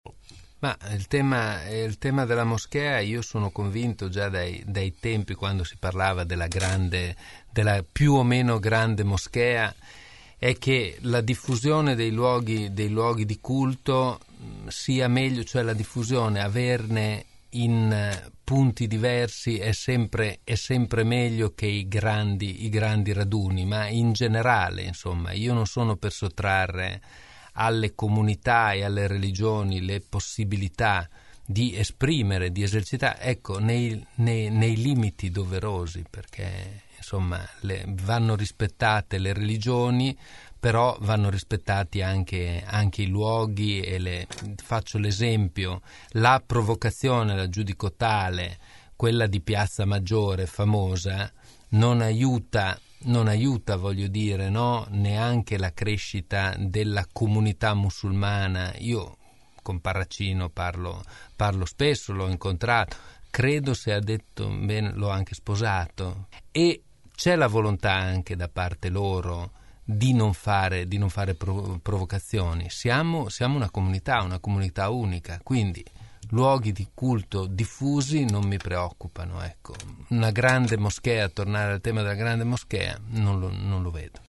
Dalla sala dibattiti centrale della Festa dell’Unità – “la prima volta da protagonista” – come afferma con orgoglio, ai nostri studi per un microfono aperto a caldo.